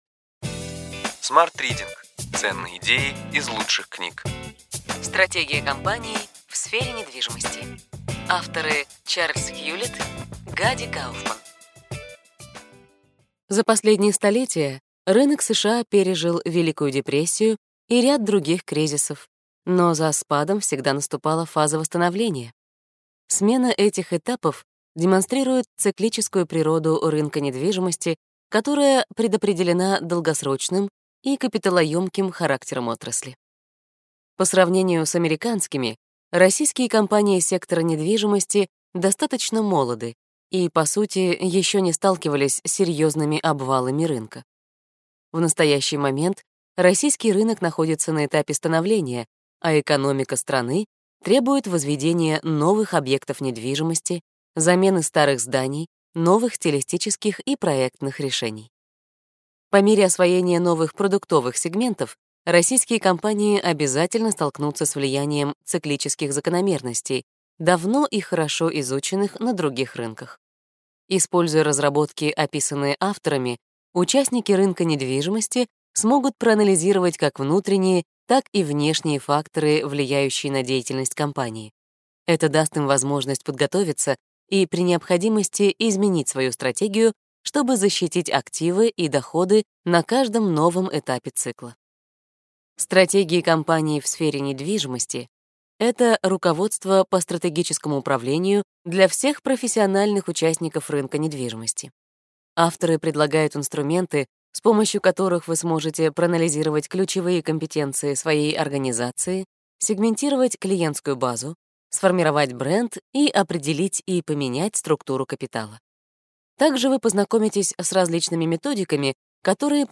Аудиокнига Ключевые идеи книги: Стратегия компаний в сфере недвижимости.